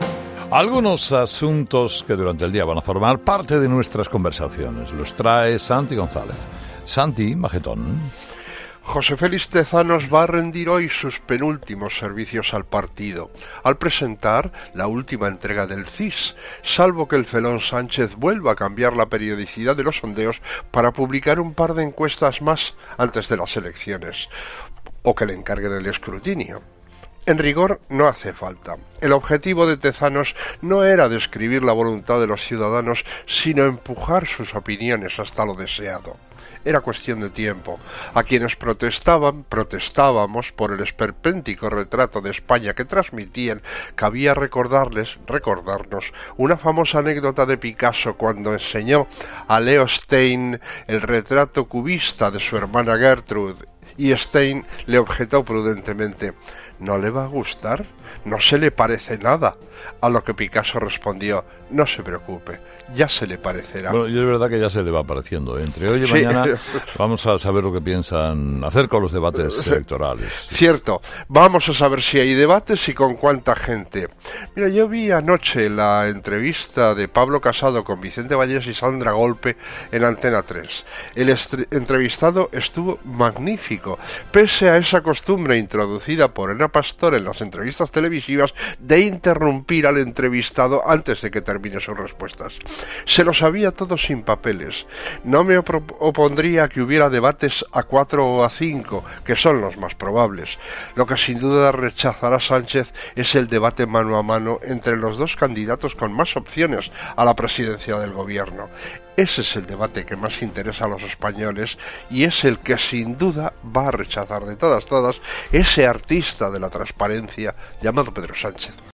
El comentario de Santi González en 'Herrera en COPE' del martes 9 de abril de 2019